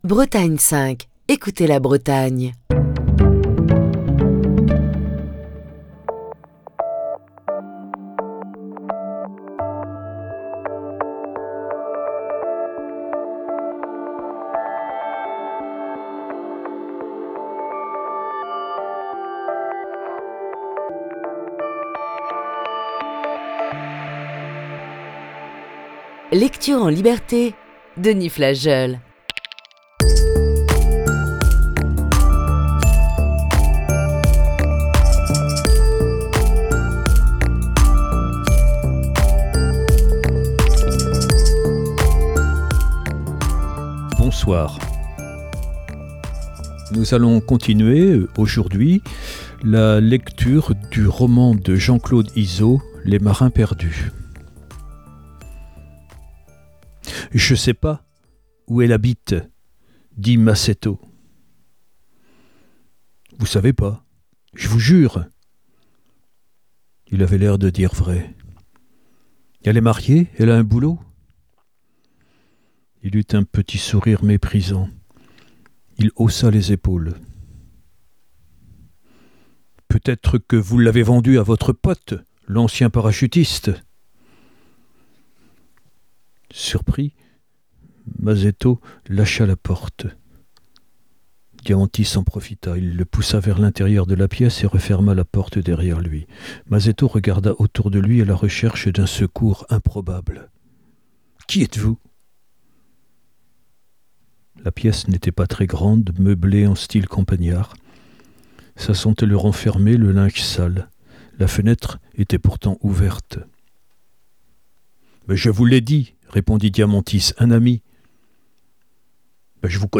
poursuit la lecture du livre